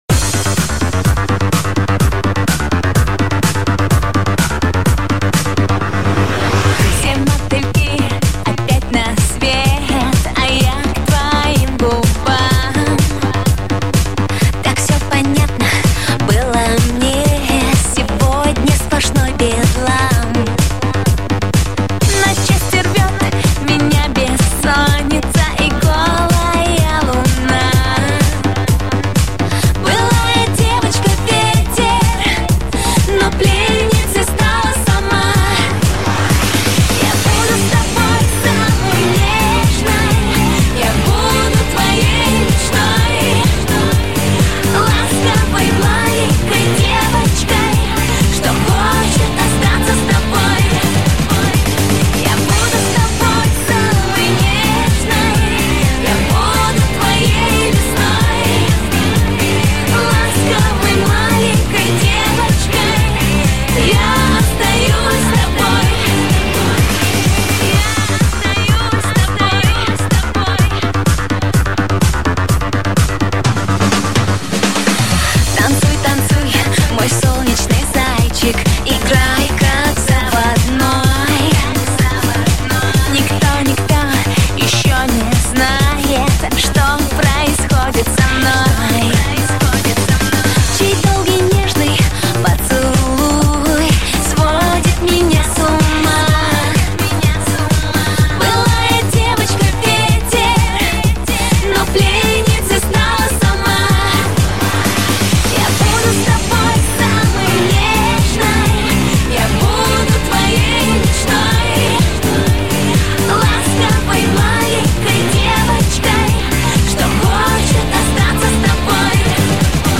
Категория: Русский Рэп/ Хип - Хоп